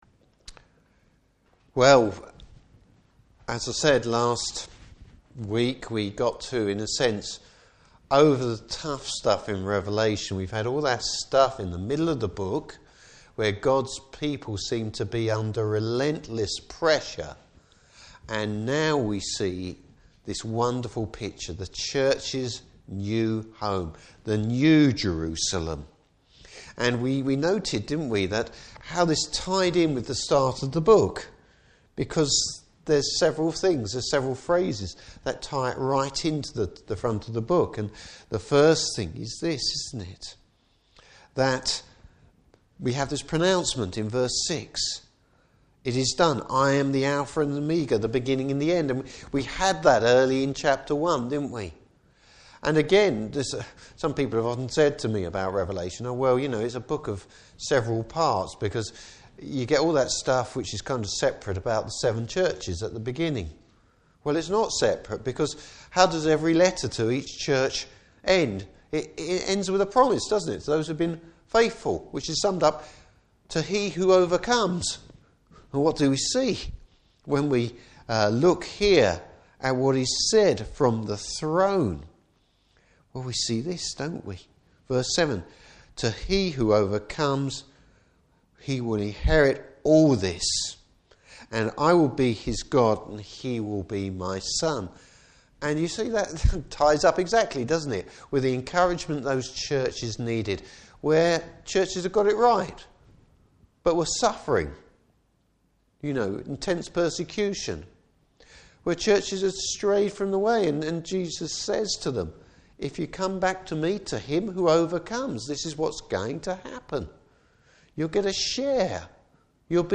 Service Type: Evening Service Bible Text: Revelation 21:22-27.